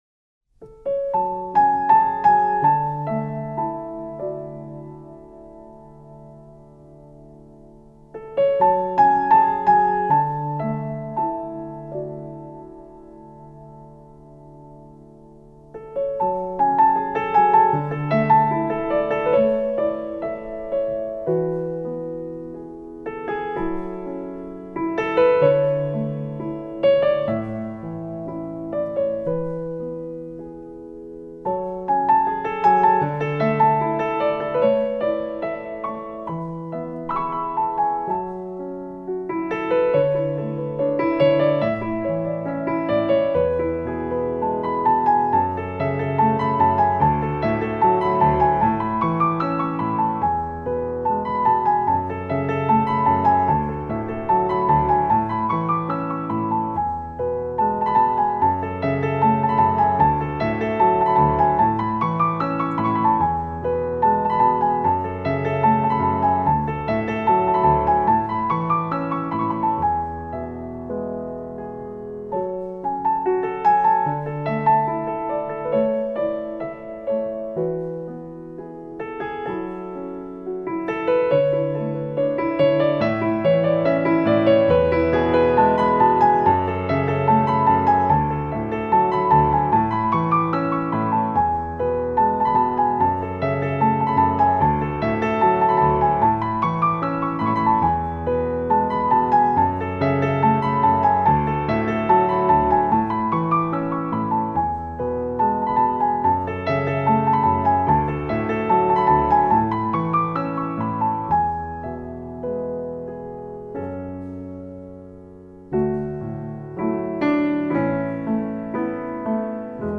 آهنگ لالایی
لالا لالایی شماره 19 – آرامبخش